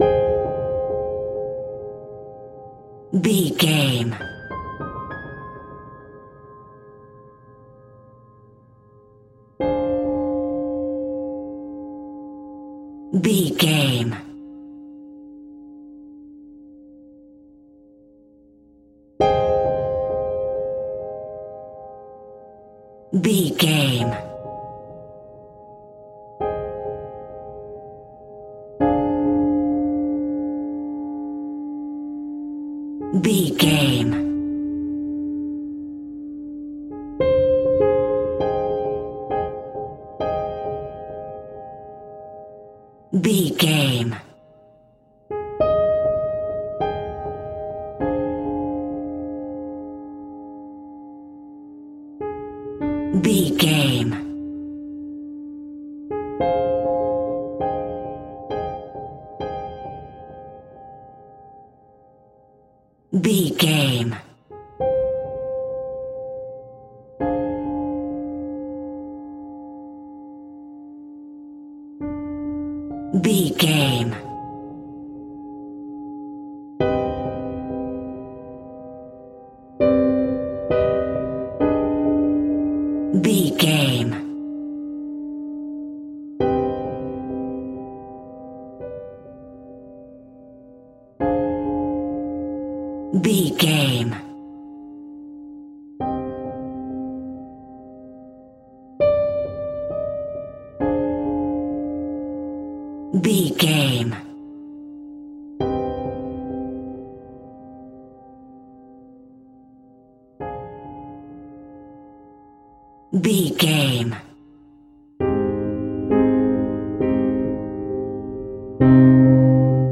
In-crescendo
Thriller
Aeolian/Minor
ominous
dark
haunting
eerie
creepy
horror music
horror piano